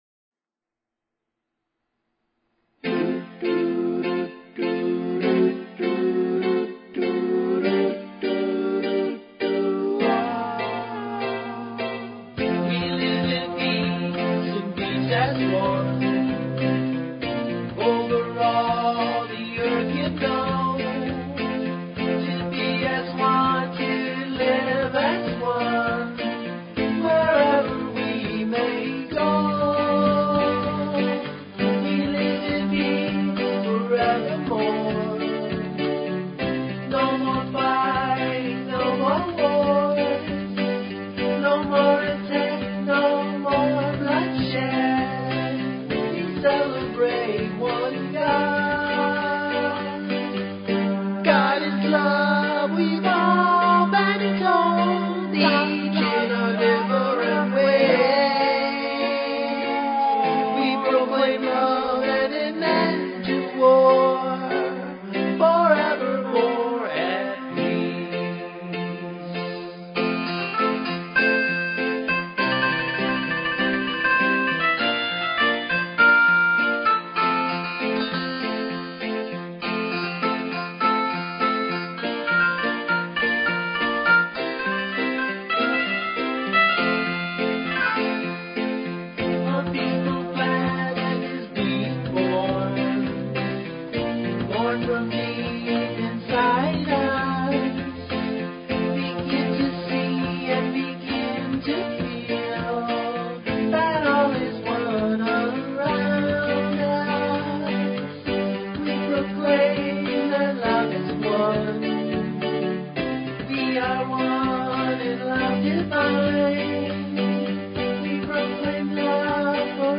Peaceful Planet Welcomes Dr. Edgar Mitchell, author of The Way of the Explorer An Apollo Astronaut's Journey Through the Material and Mystical Worlds!